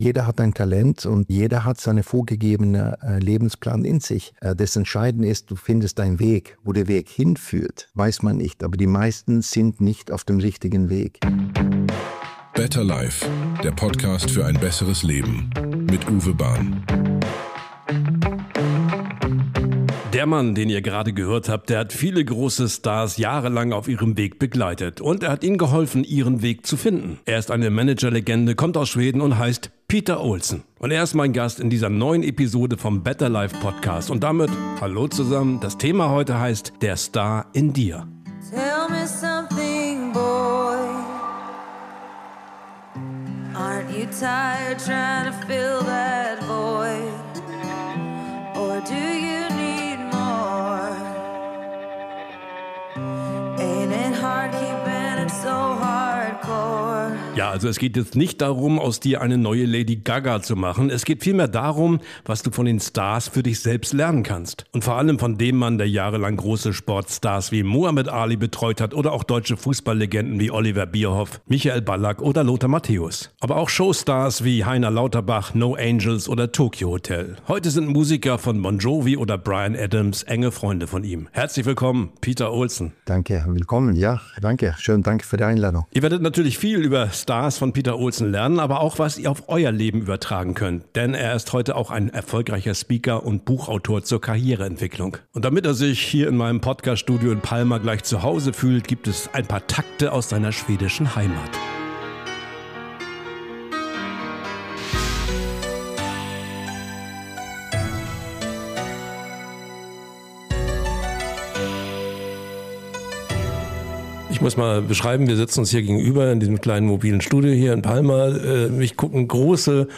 Und als Gast in diesem Podcast.